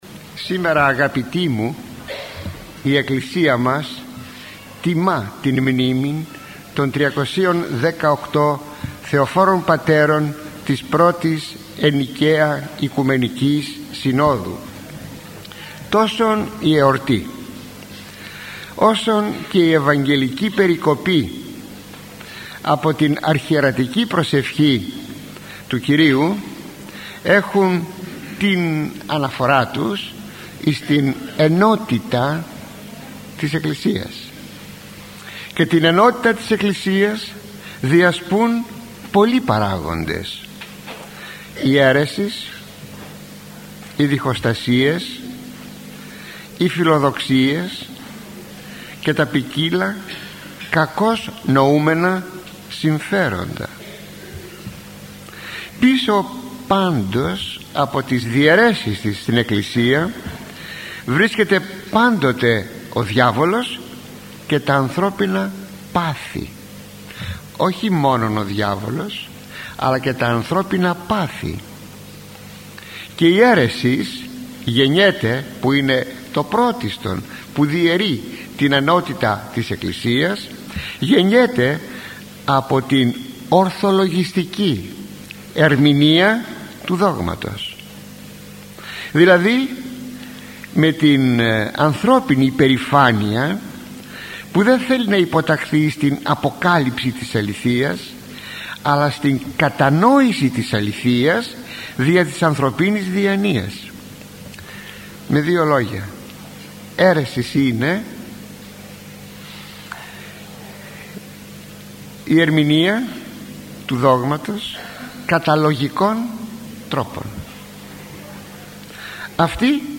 Κυριακή των Αγίων Πατέρων της Α. Οικουμενικής Συνόδου: Η ενότητα της Εκκλησίας – ηχογραφημένη ομιλία του Μακαριστού Αρχιμ. Αθανασίου Μυτιληναίου (αρχείο ήχου, mp3).
Ο λόγος του ήταν πάντοτε μεστός, προσεγμένος, επιστημονικός αλλά συνάμα κατανοητός και προσιτός, ακόμη και για τους πλέον απλοϊκούς ακροατές του.